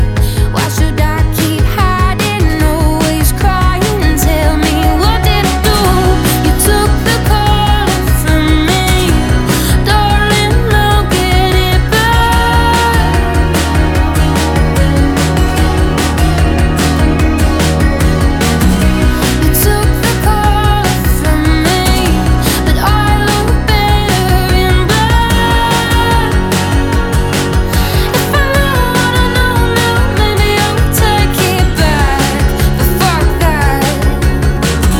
Alternative
Жанр: Альтернатива